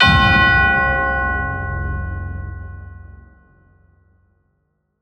StartFX.wav